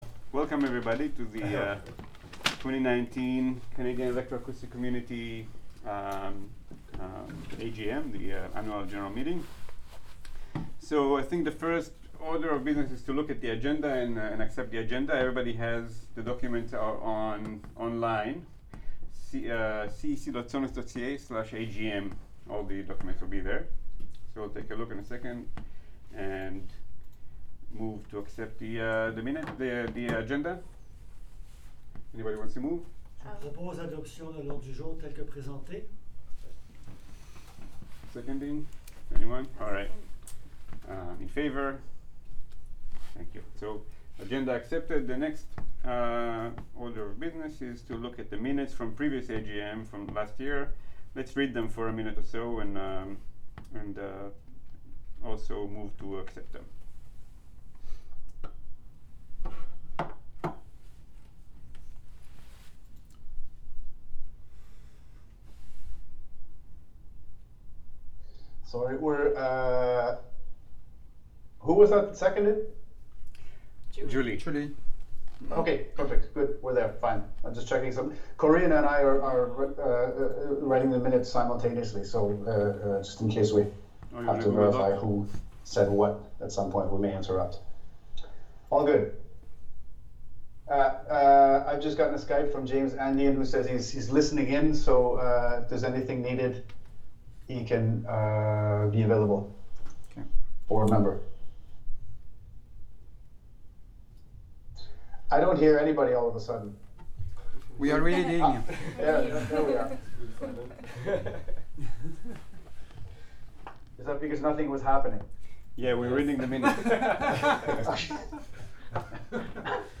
2019 AGM — Annual General Meeting / AGA 2019 — Assemblée générale annuelle
The audio recording of the 2019 AGM is available here in MP3 format .